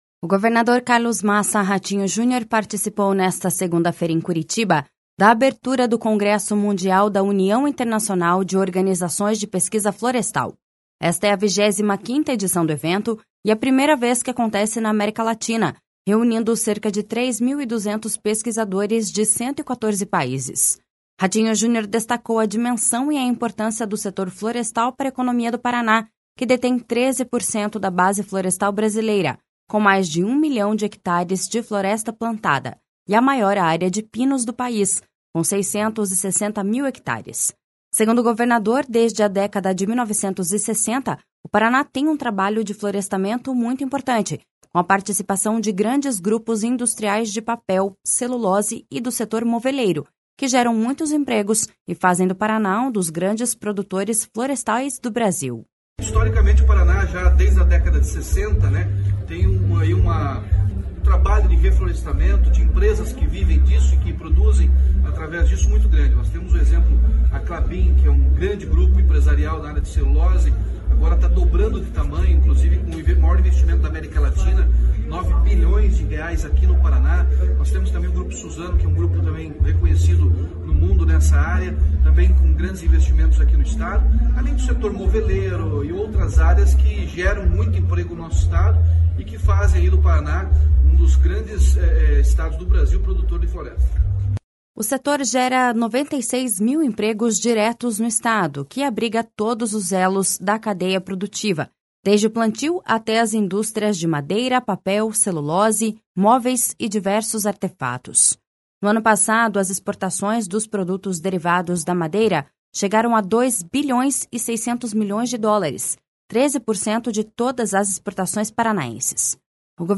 O governador Carlos Massa Ratinho Junior participou nesta segunda-feira, em Curitiba, da abertura do Congresso Mundial da União Internacional de Organizações de Pesquisa Florestal.
Segundo o governador, desde a década de 1960, o Paraná tem um trabalho de florestamento muito importante, com a participação de grandes grupos industriais de papel, celulose e do setor moveleiro, que geram muitos empregos e fazem do Paraná um dos grandes produtores florestais do Brasil.// SONORA RATINHO JUNIOR.//
Para o secretário de Estado da Agricultura e Abastecimento, Norberto Ortigara, o Paraná tem condições de ampliar ainda mais a produção na área.// SONORA NORBERTO OTIGARA.//